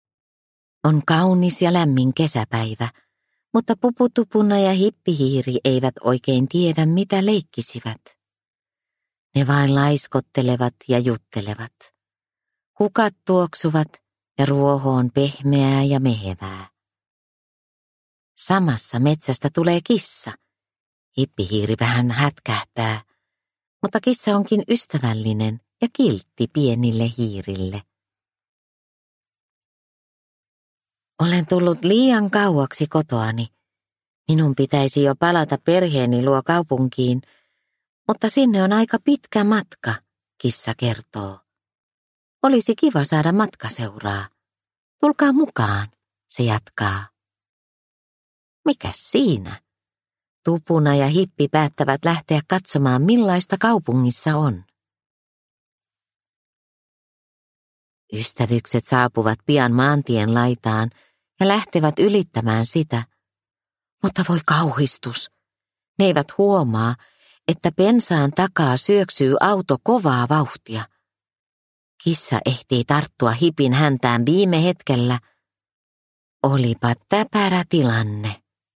Pupu Tupuna matkalla kaupunkiin – Ljudbok – Laddas ner